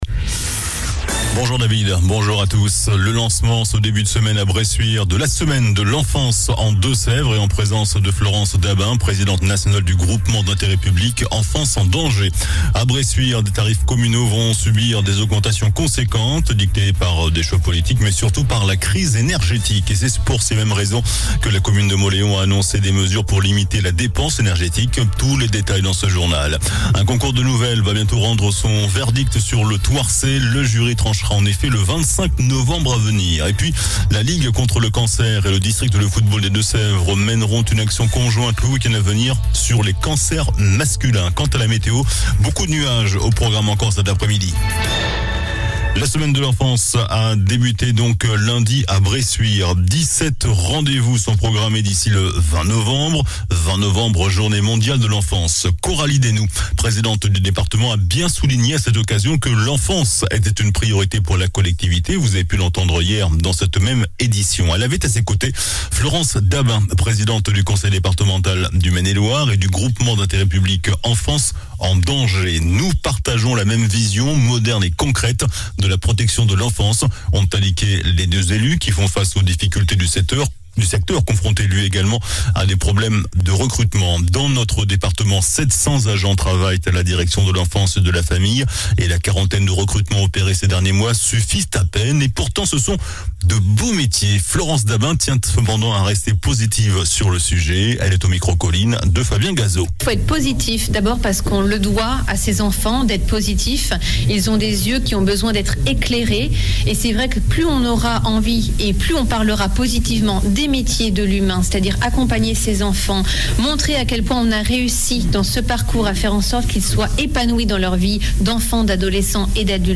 JOURNAL DU MERCREDI 16 NOVEMBRE ( MIDI )